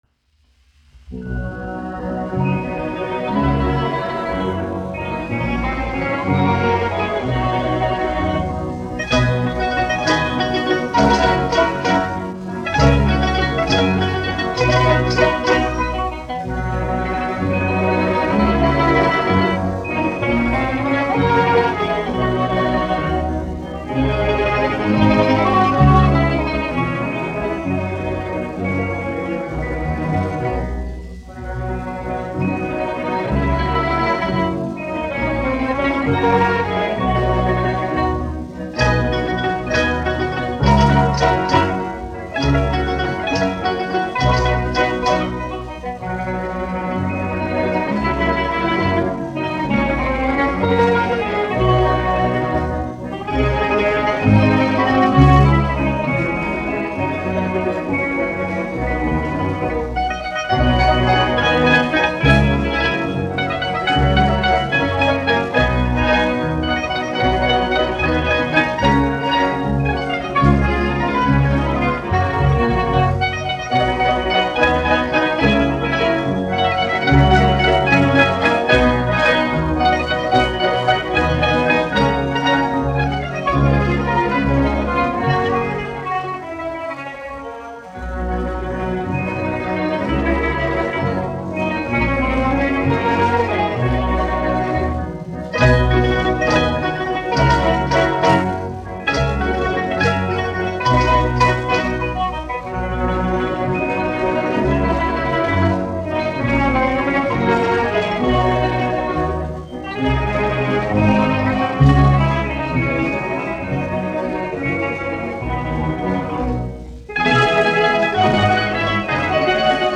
1 skpl. : analogs, 78 apgr/min, mono ; 25 cm
Valši
Orķestra mūzika
Mandolīnu orķestris
Skaņuplate